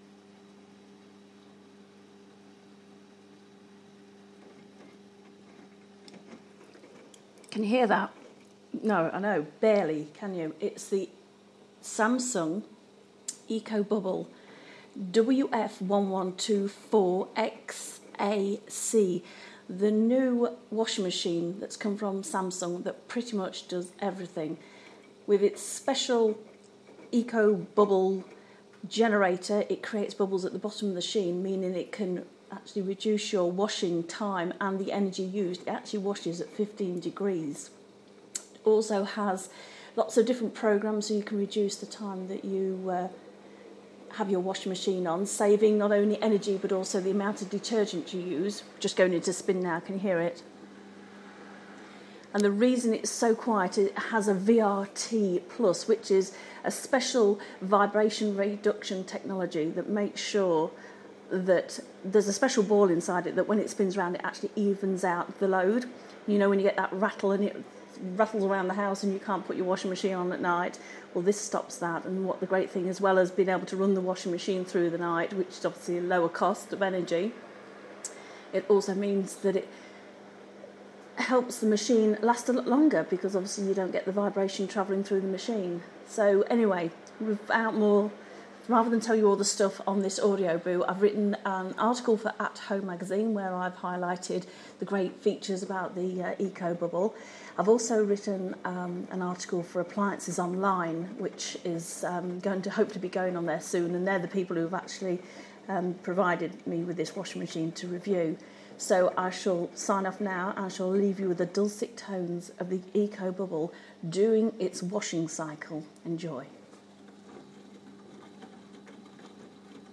The silent purr of the Ecobubble washing machine